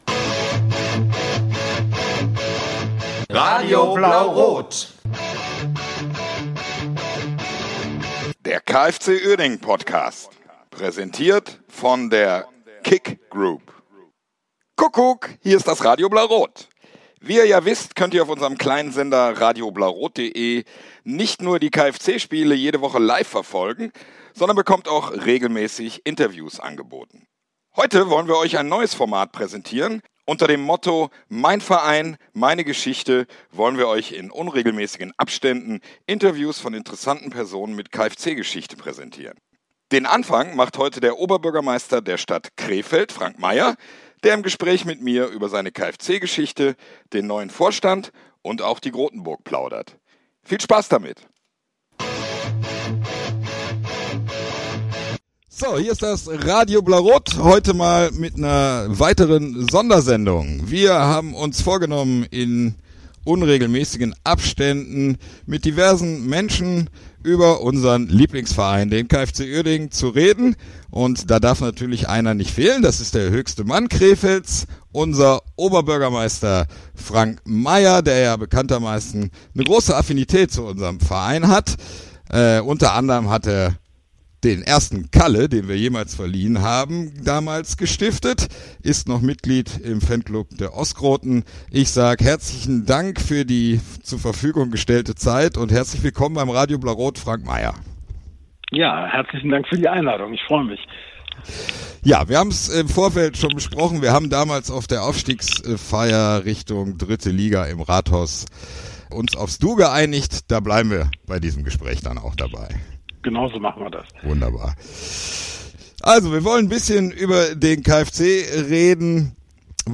Gespräch mit Krefelds Oberbürgermeister Frank Meyer über den KFC Uerdingen 05 e.V.